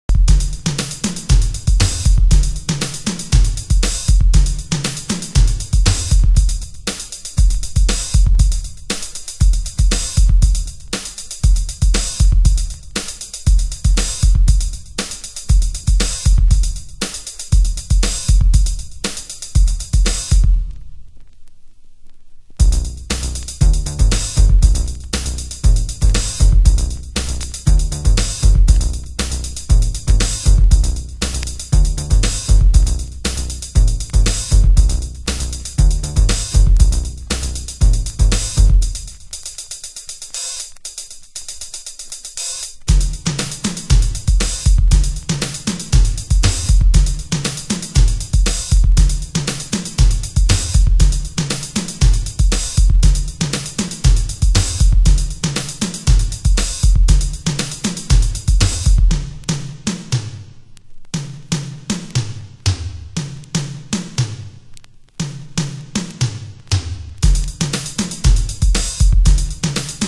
盤質：目立つ傷はありませんが、少しチリパチノイズ有